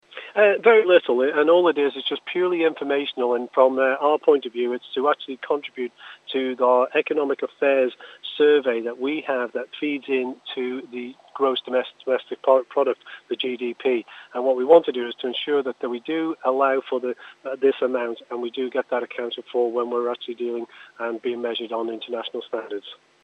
Laurence Skelly says it won't require much time to complete: